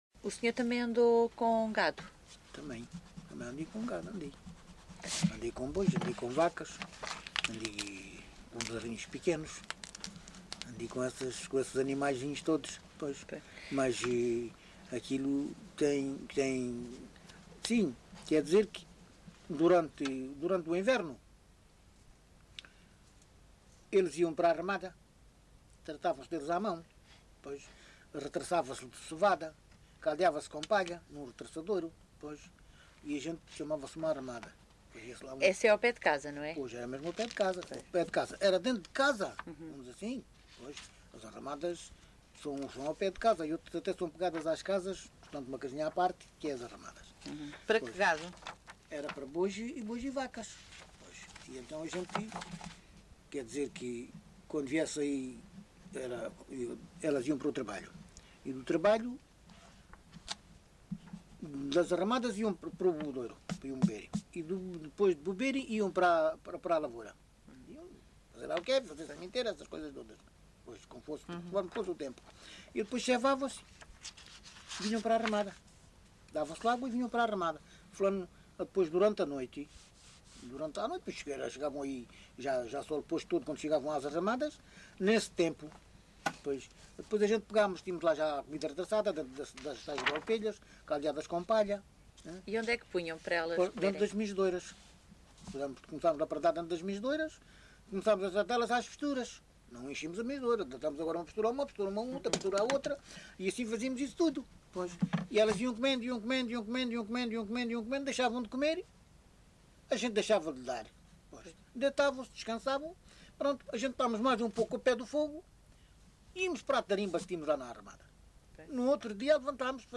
LocalidadeVale Chaim de Baixo (Odemira, Beja)